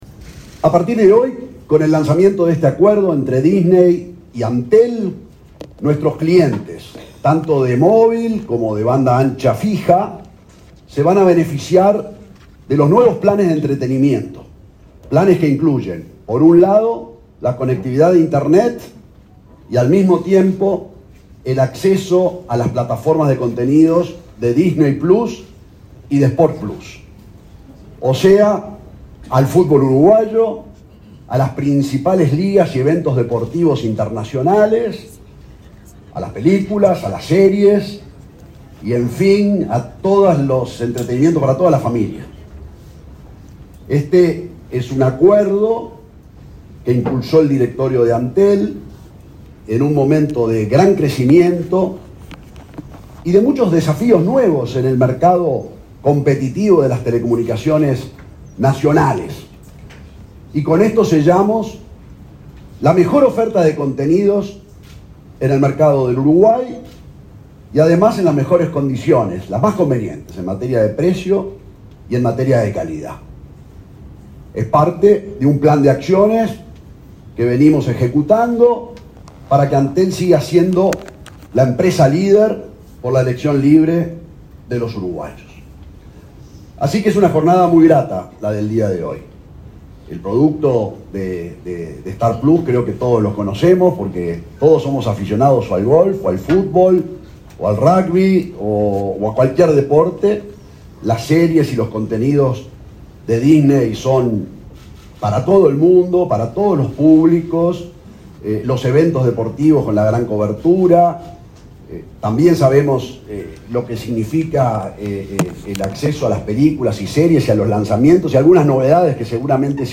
Palabras del presidente de Antel, Gabriel Gurméndez